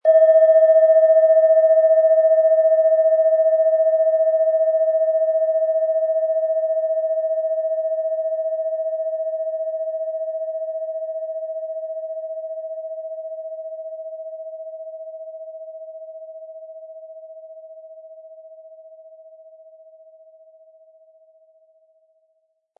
Wie klingt diese tibetische Klangschale mit dem Planetenton Alphawelle?
Um den Originalton der Schale anzuhören, gehen Sie bitte zu unserer Klangaufnahme unter dem Produktbild.
Durch die traditionsreiche Herstellung hat die Schale stattdessen diesen einmaligen Ton und das besondere, bewegende Schwingen der traditionellen Handarbeit.
Ein die Schale gut klingend lassender Schlegel liegt kostenfrei bei, er lässt die Planetenklangschale Alphawelle harmonisch und angenehm ertönen.
MaterialBronze